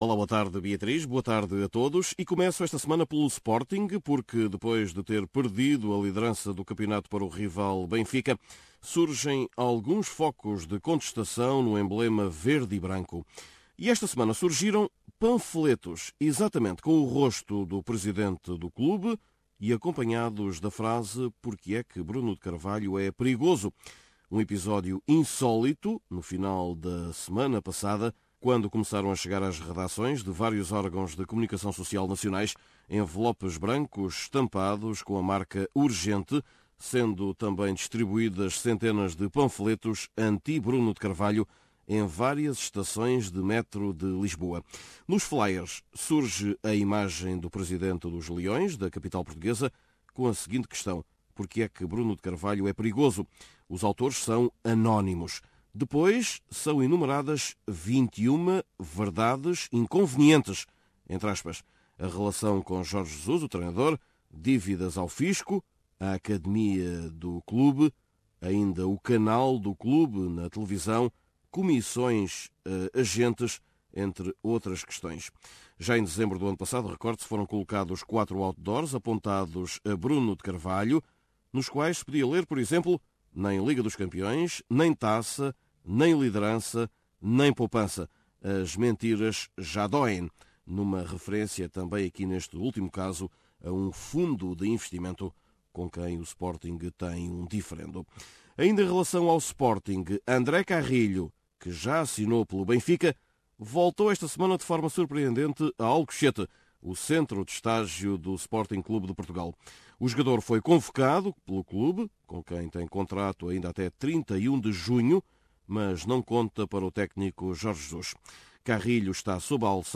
Neste boletim semanal, falamos ainda da Liga portuguesa e da história, entre outras, de uma atleta que perdeu um título de atletismo porque se enganou… no número de voltas da corrida.